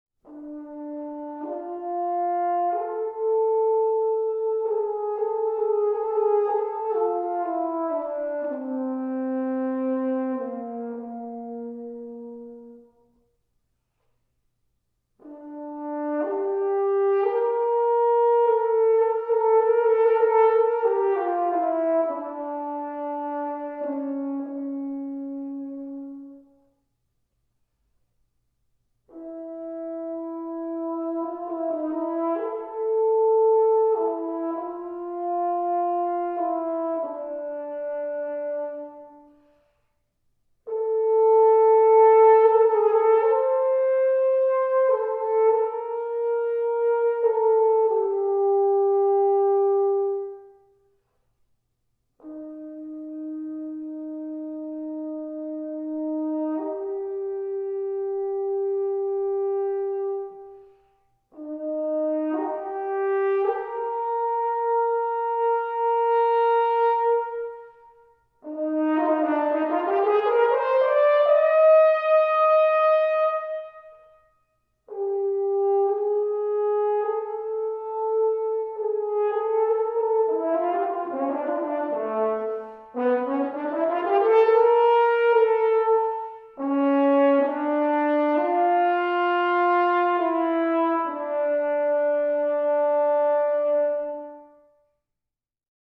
Recent favorite, unedited solo and chamber music performances
22, 23, 26, 27, 31, 35, 36, 37, Western Michigan University Dalton Recital Hall, 2019